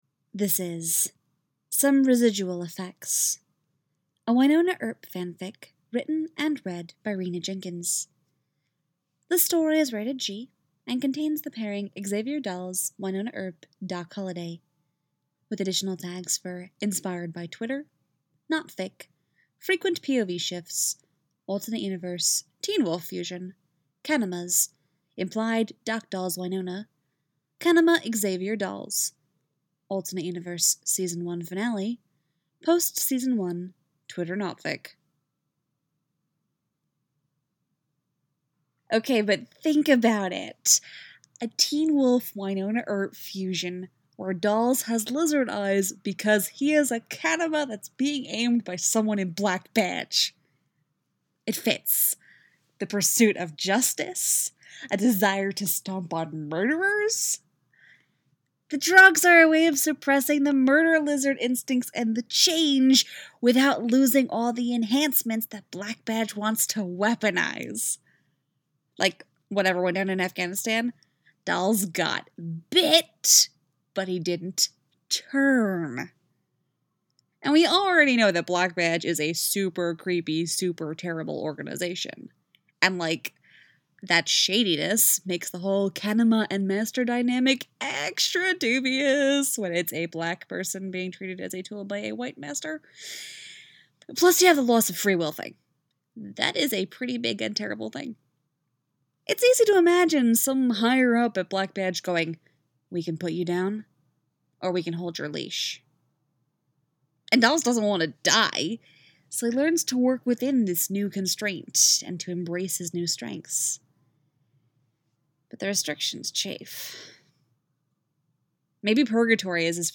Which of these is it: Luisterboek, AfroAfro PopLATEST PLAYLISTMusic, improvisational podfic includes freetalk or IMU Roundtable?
improvisational podfic includes freetalk